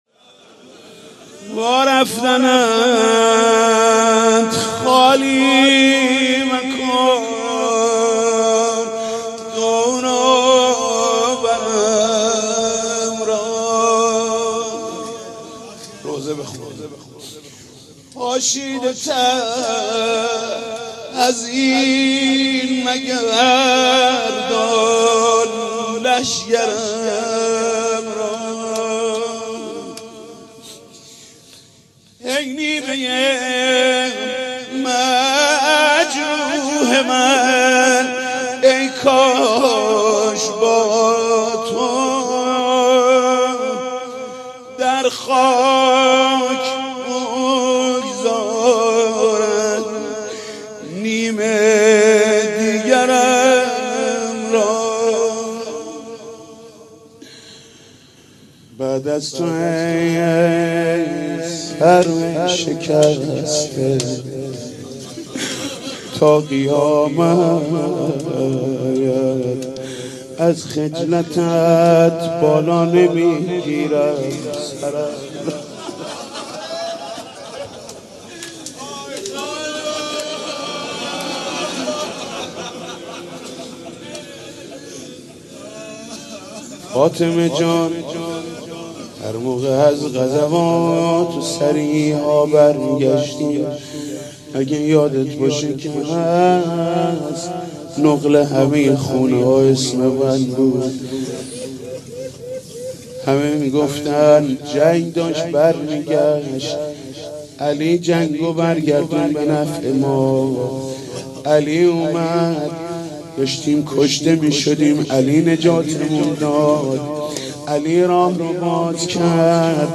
مناسبت : شهادت حضرت فاطمه زهرا سلام‌الله‌علیها
مداح : محمود کریمی قالب : روضه